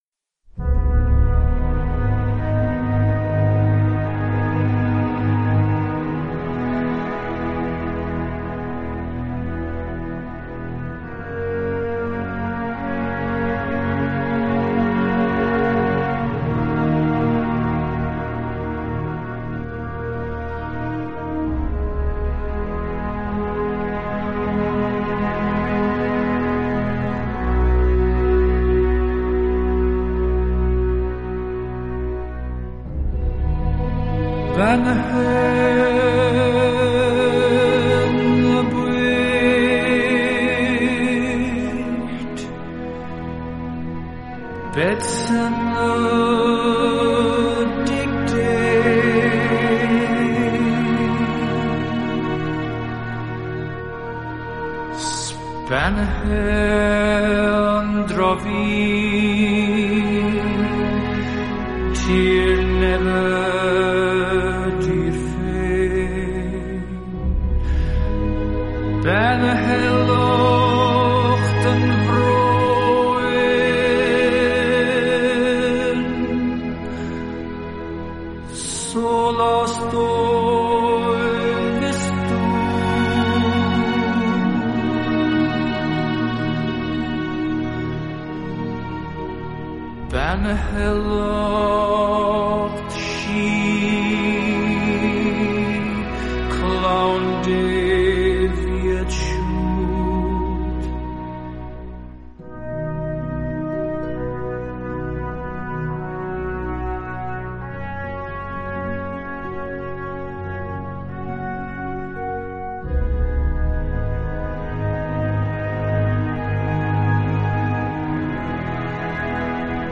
Original Motion Picture Soundtrack